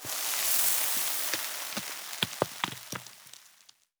expl_debris_sand_01.ogg